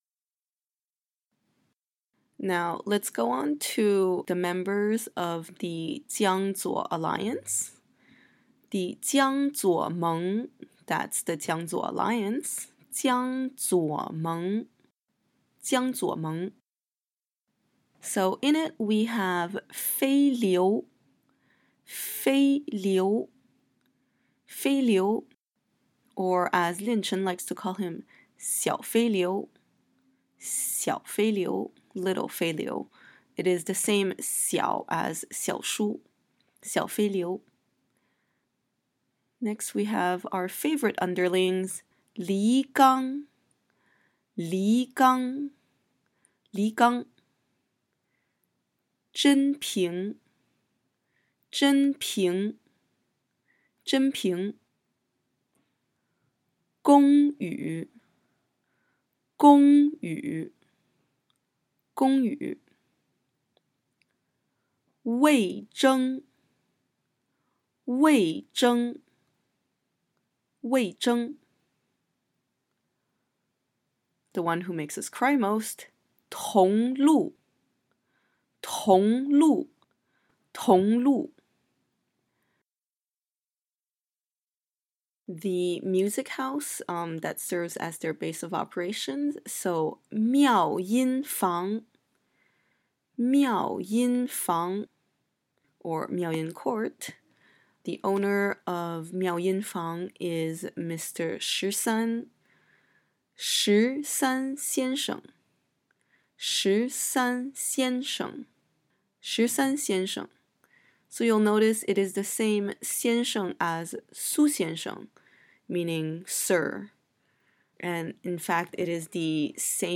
Nirvana in Fire pronunciation guide
I believe my accent is fairly Standard but feel free to disagree.